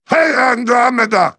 synthetic-wakewords
ovos-tts-plugin-deepponies_Heavy_en.wav